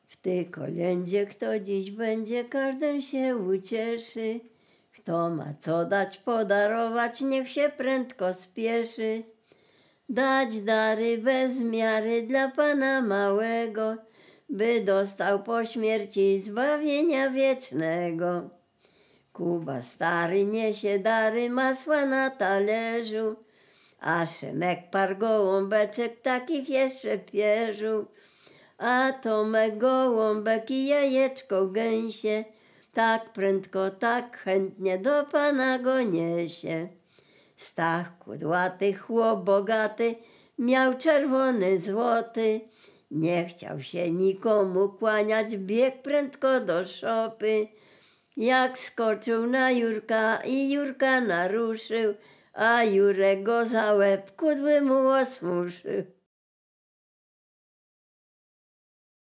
Dolny Śląsk, powiat bolesławiecki, gmina Nowogrodziec, wieś Zebrzydowa
Kolęda